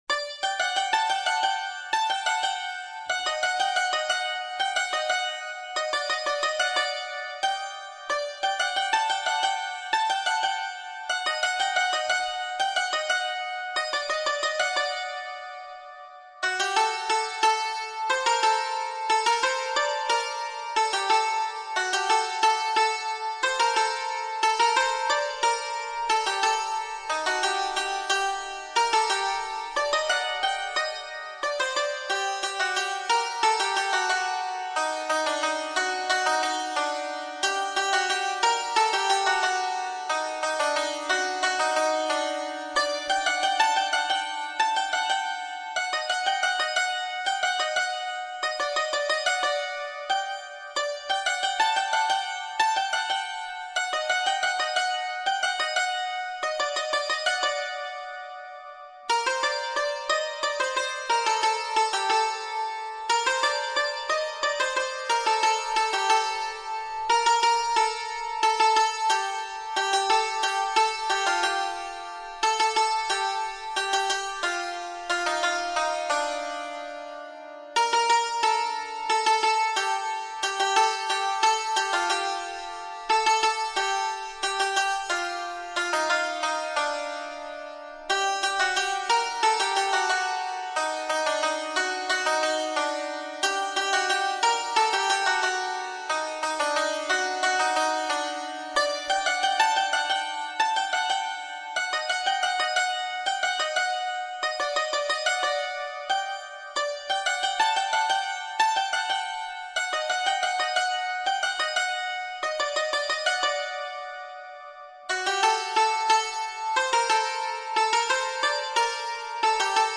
کوک سل مینور هارمونیک ( خرک دوم فا دیز خرک نهم فا بکار